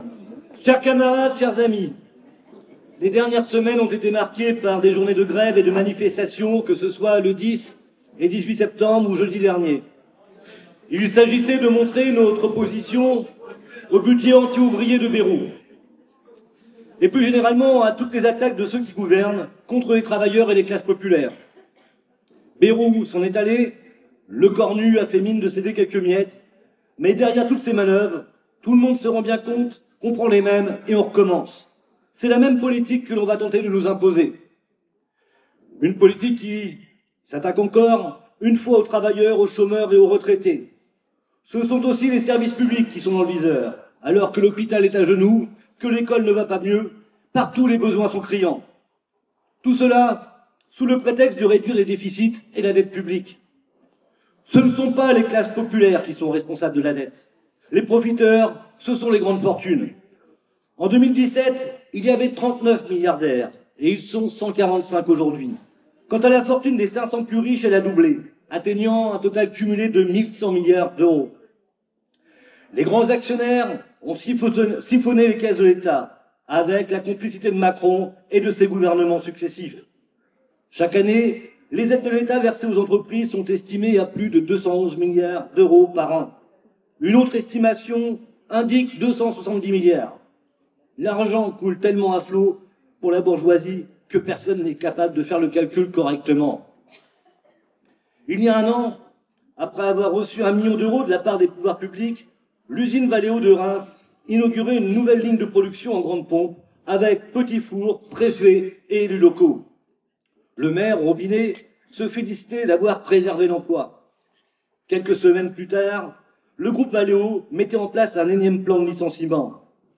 LO : Intervention
lors de la fête de Reims du dimanche 5 octobre 2025